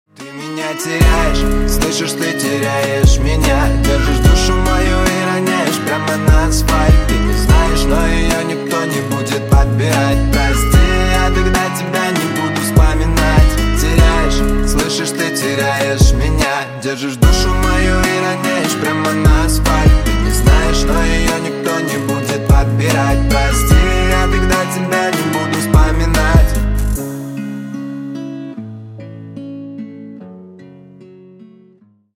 Грустные Рингтоны
Рэп Хип-Хоп Рингтоны